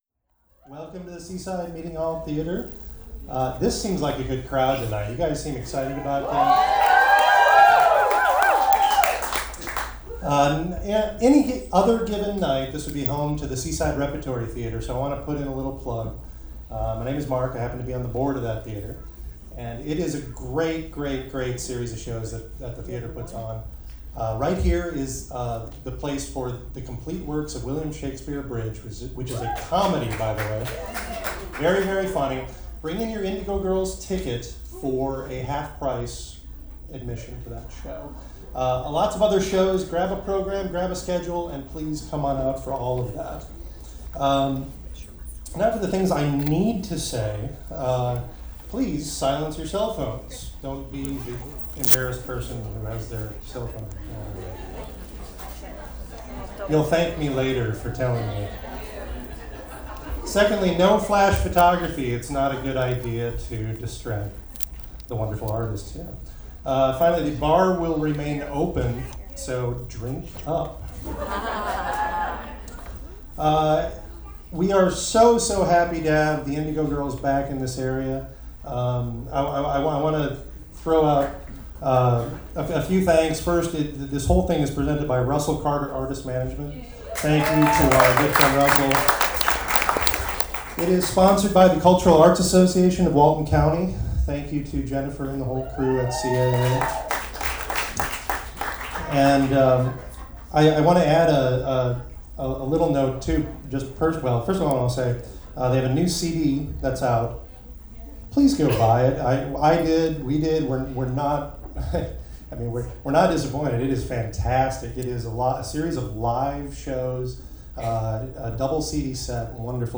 lifeblood: bootlegs: 2010-07-12: the rep theater - seaside, florida (early show)